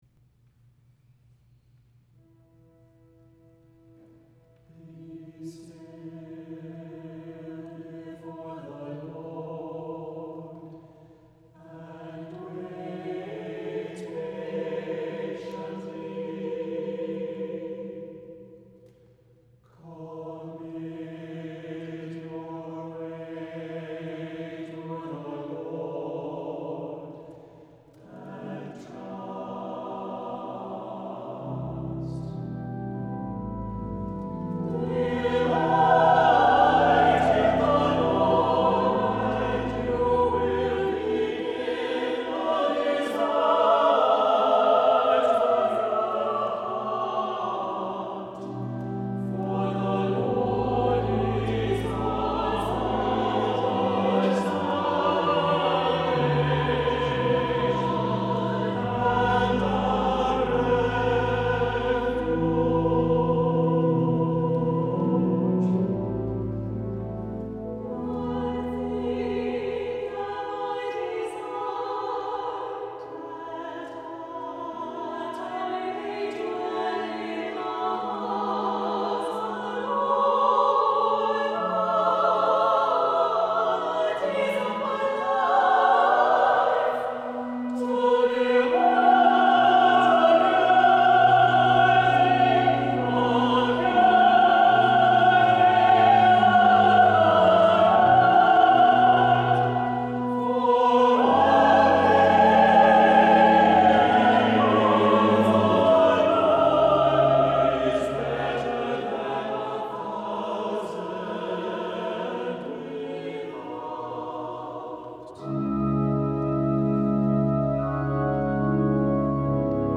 • Music Type: Choral
• Voicing: SATB
• Accompaniment: Organ
• -especially sensitive word painting and text rhythm